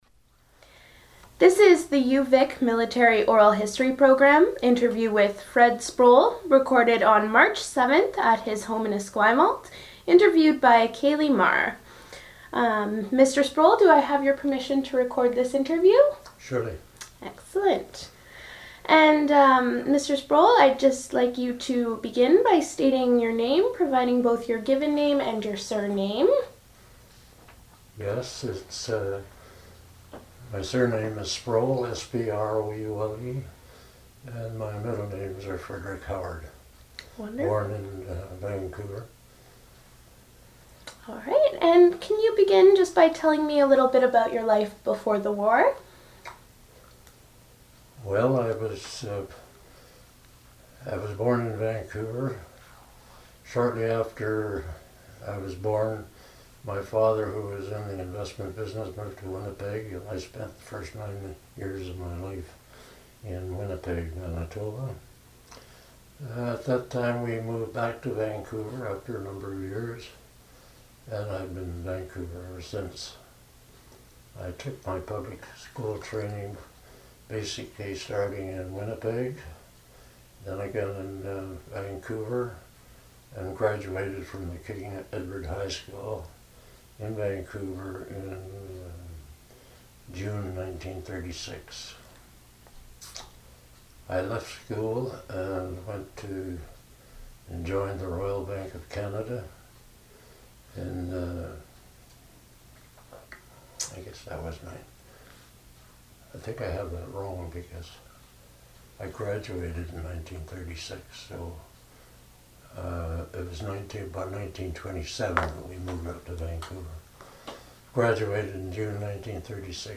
my Air Force recollections